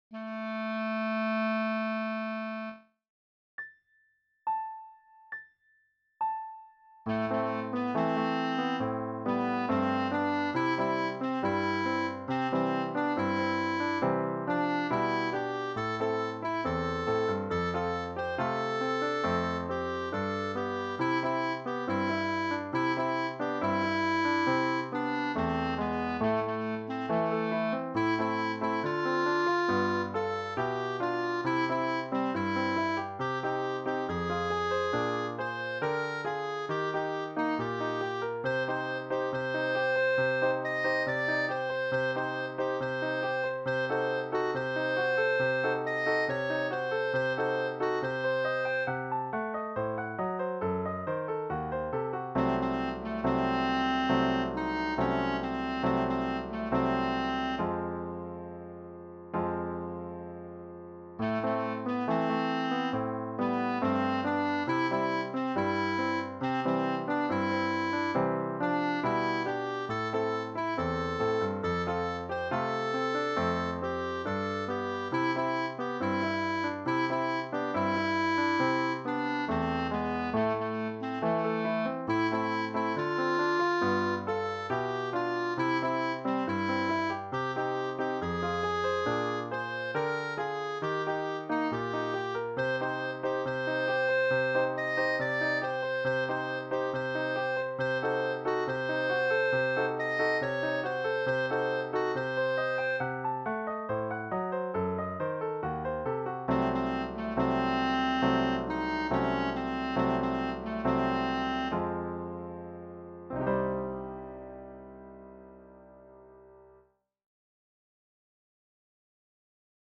Ici, on pourra acceder à une version accompagnée des mélodies et chansons apprises lors de nos cours.
Une belle quoique triste chanson chilienne des années 70 (pour apprendre le “Tirimri”)